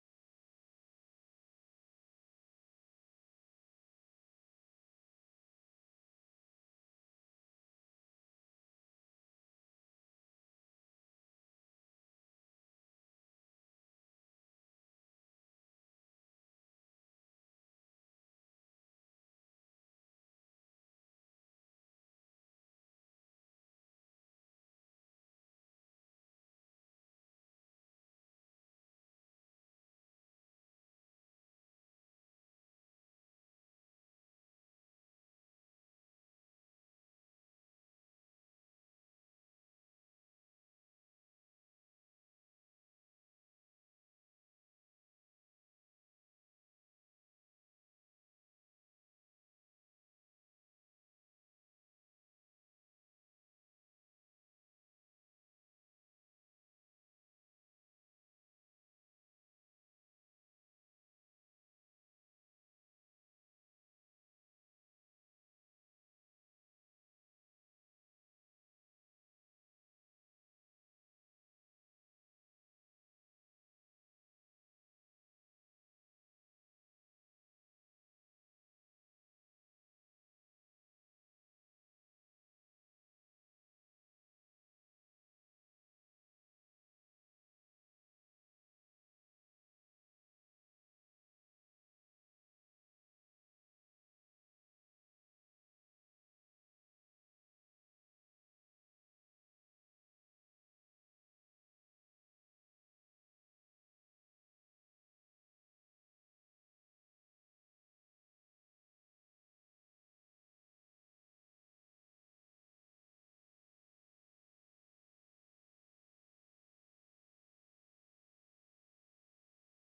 There are no notes for this sermon.